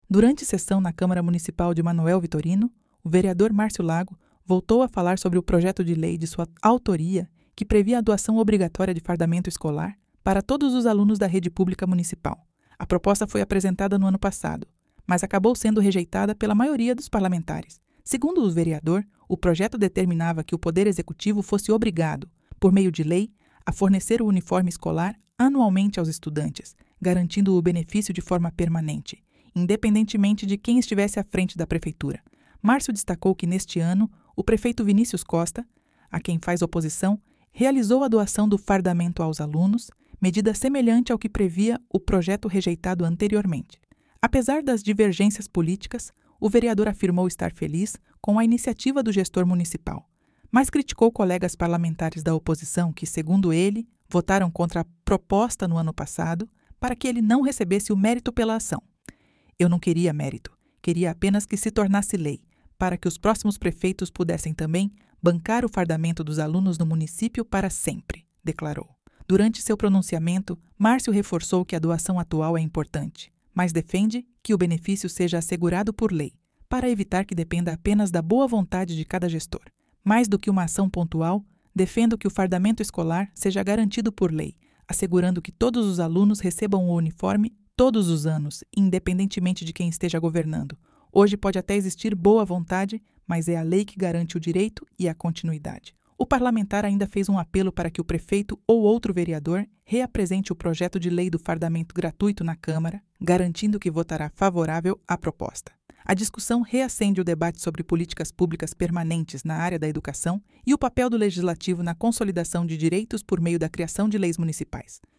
Durante sessão na Câmara Municipal de Manoel Vitorino, o vereador Marcio Lago voltou a falar sobre o projeto de lei de sua autoria que previa a doação obrigatória de fardamento escolar para todos os alunos da rede pública municipal.